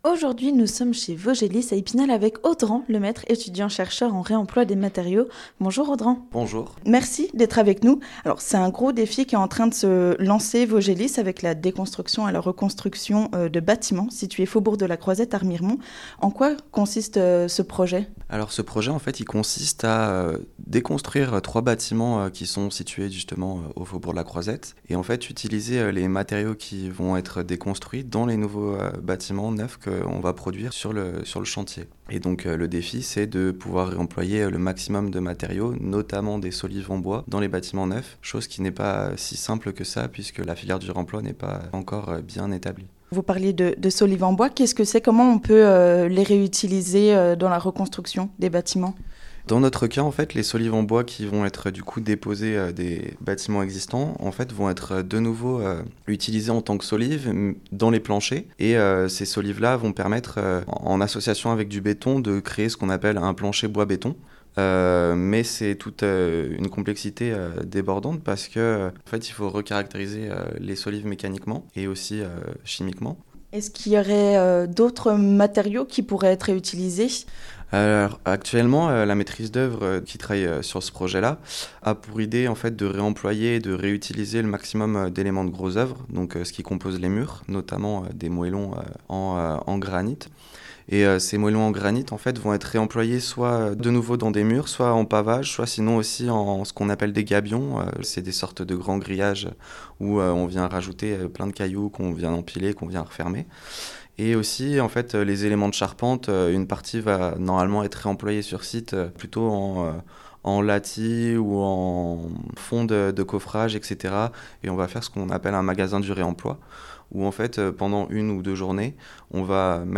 Reportage Vosges FM